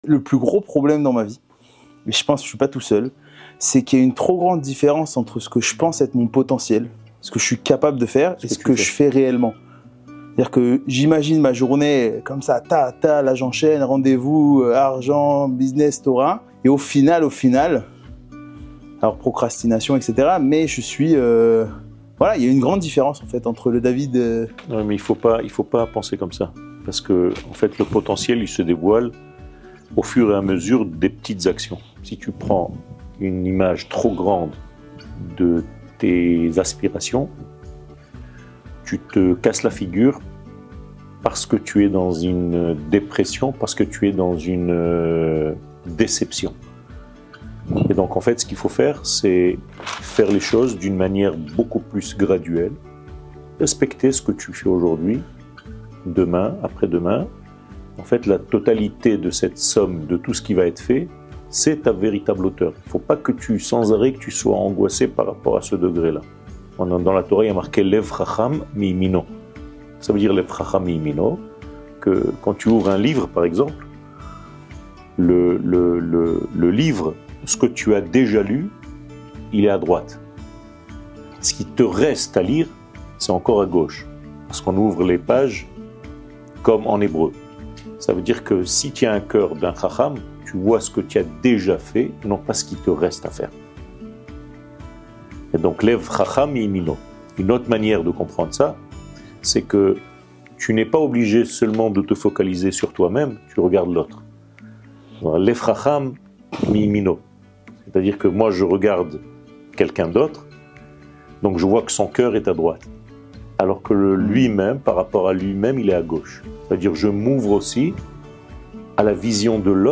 שיעור מ 22 מאי 2024
שיעורים קצרים